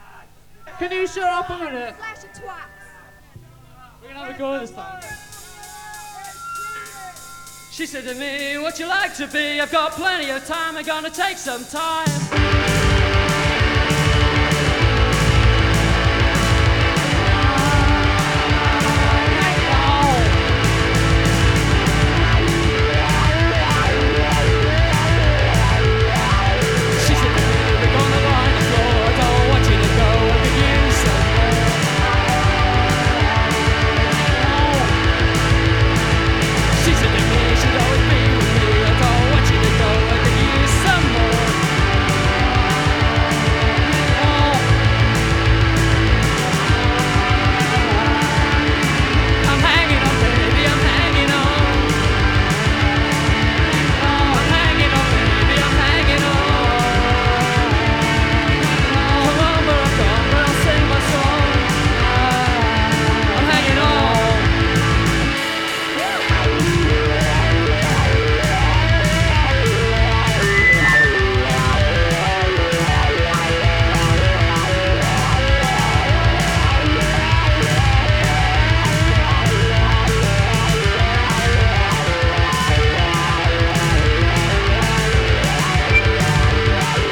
暴走ギターポップ
インディーポップ
通算では4作目にあたるライヴ盤。
[7track LP]＊時折パチ・ノイズ。